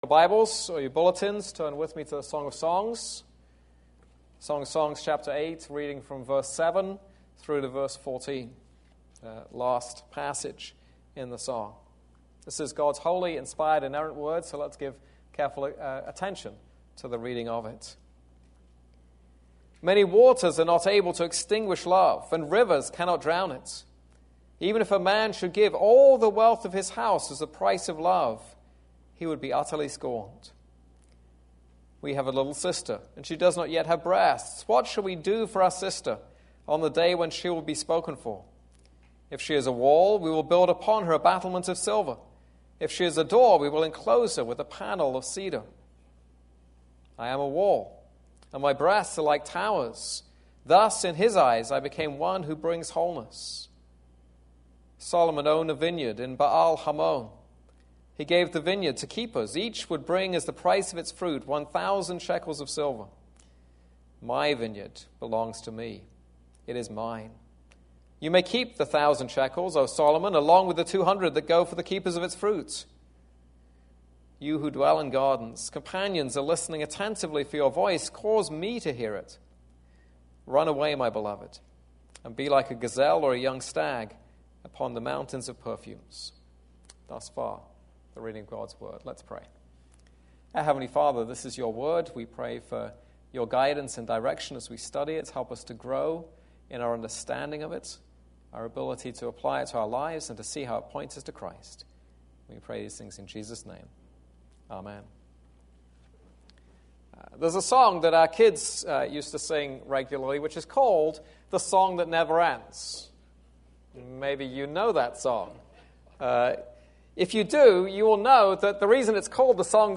This is a sermon on Song of Songs 8:7-14.